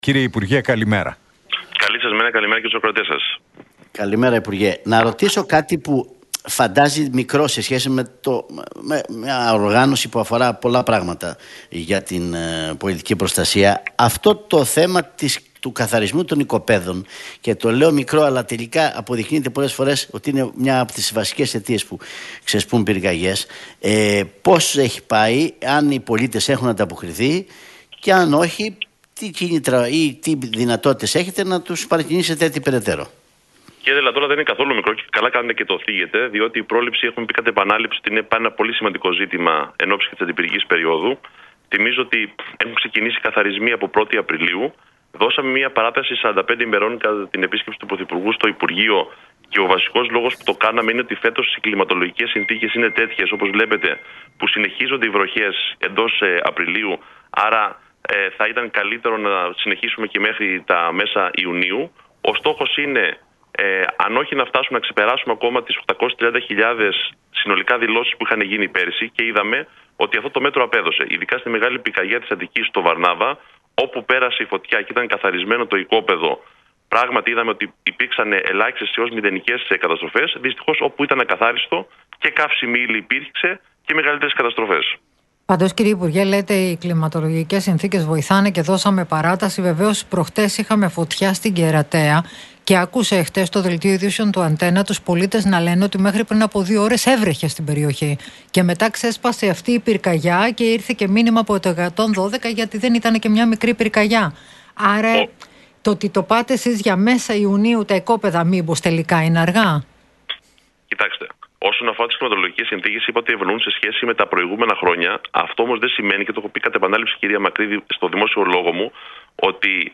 Κεφαλογιάννης στον Realfm 97,8: 1.365 ενάρξεις πυρκαγιών τον Μάρτιο - 82 drones για επιτήρηση φέτος από 45 που ήταν πέρυσι